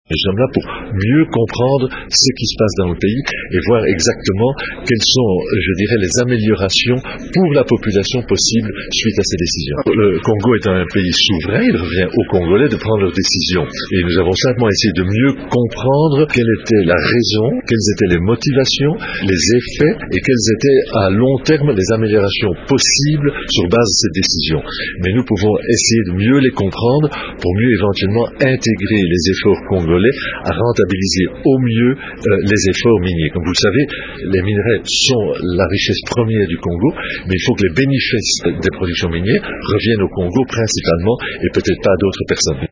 L’ambassadeur de Belgique en RDC, Dominique Struye de Swielande